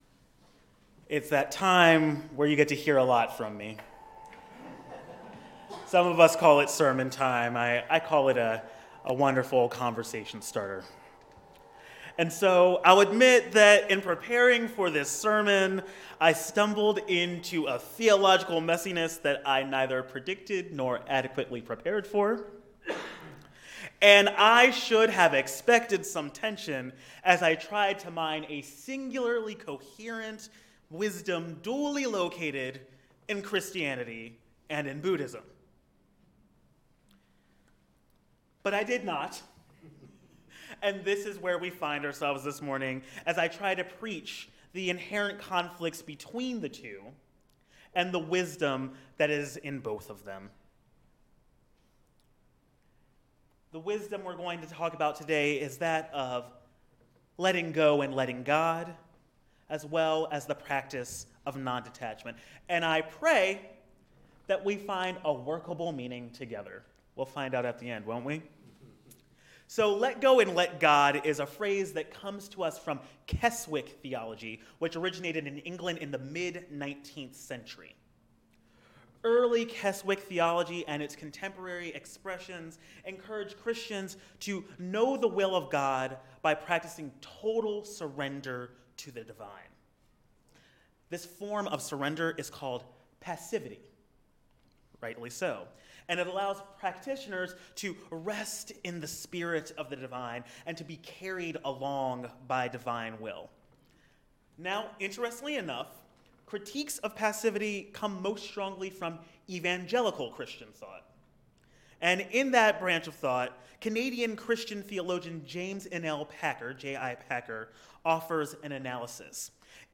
Gather with us for a service that invites us to identify how we might let go and let mystery move in our lives.